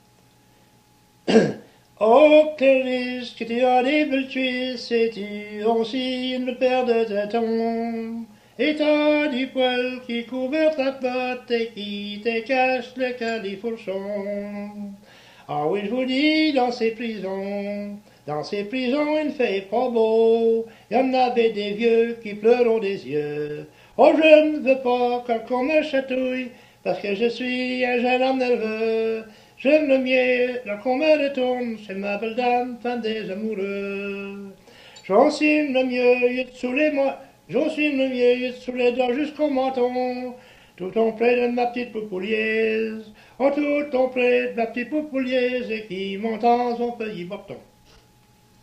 Chanson Item Type Metadata